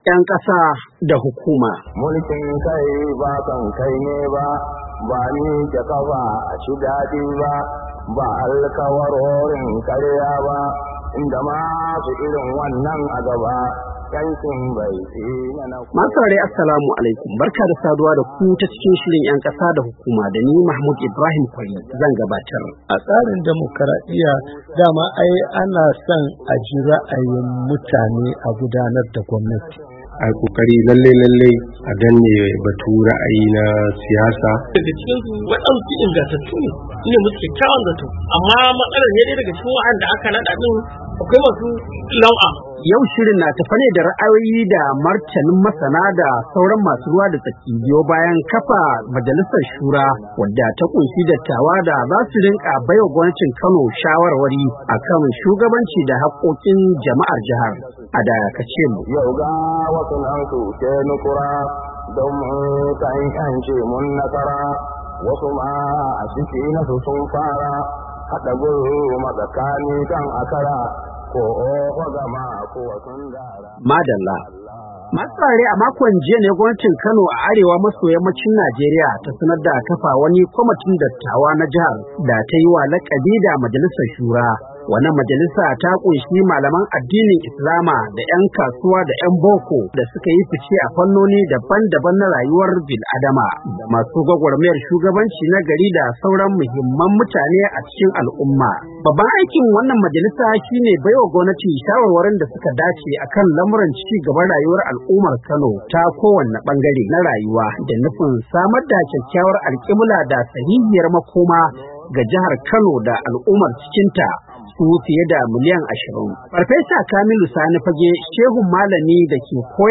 Shirin 'Yan Kasa Da Hukuma na wannan makon na tafe da ra’ayoyi da martanin masana da sauran masu ruwa da tsaki a kan kafa majalisar shura da gwamnatin jihar Kano a Najeriya ta yi a makon jiya, domin bata shawarwari a kan tafiyar da hakkokin Jama'ar jihar.